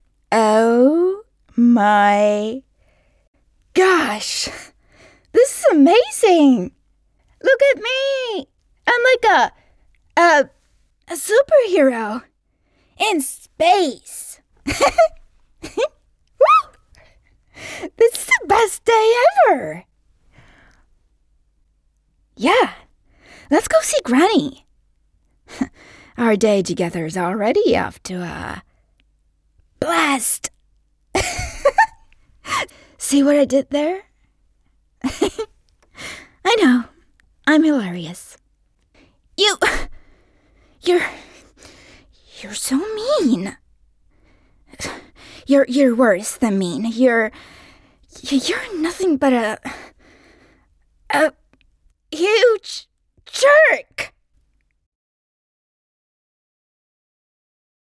Animación
Soy locutora estonia nativa y trabajo tanto en estonio como en inglés, ¡con un ligero acento!
Como hablante nativo de estonio, normalmente tengo un ligero acento.
Identidades tanto masculinas como femeninas.